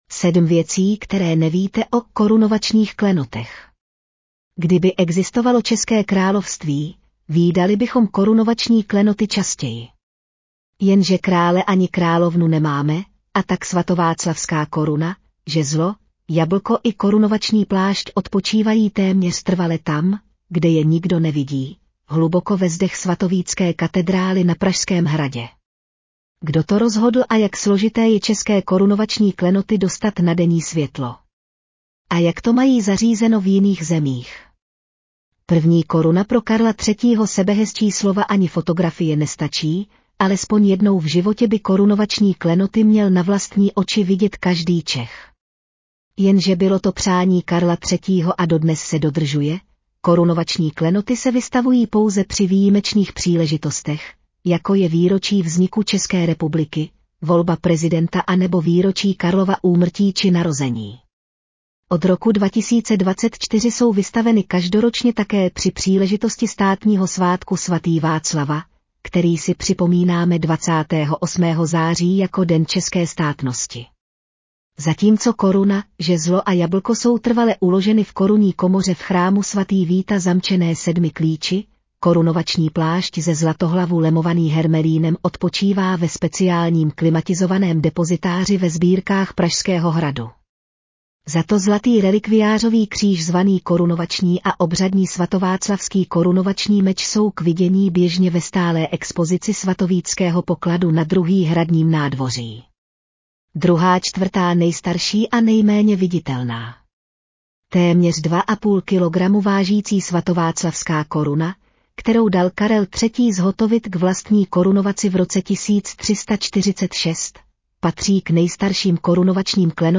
Audio verze článku 7 věcí, které nevíte o… korunovačních klenotech